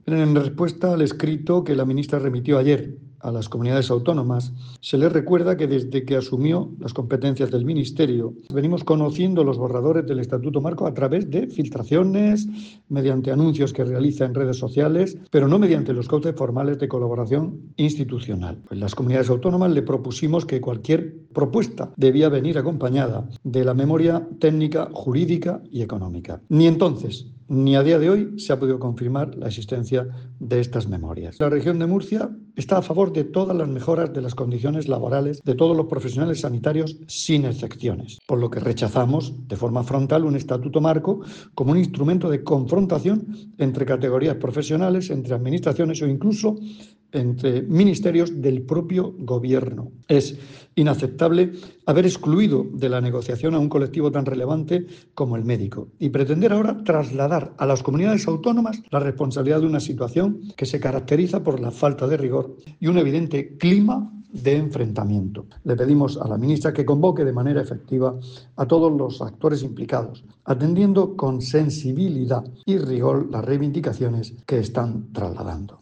Declaraciones del consejero de Salud, Juan José Pedreño, sobre la aprobación del Estatuto Marco del Sistema Nacional de Salud.